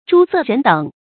諸色人等 注音： ㄓㄨ ㄙㄜˋ ㄖㄣˊ ㄉㄥˇ 讀音讀法： 意思解釋： 各種各樣、各色各等的人們。